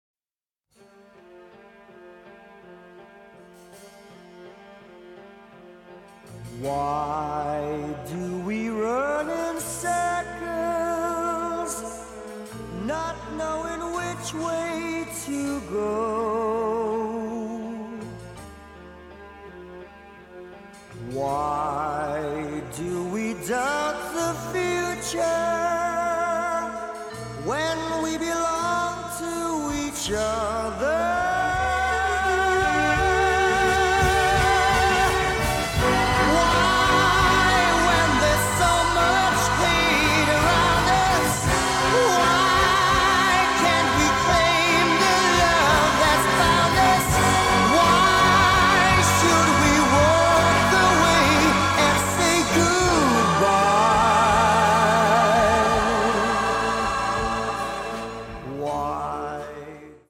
(Titoli di testa vocal) 01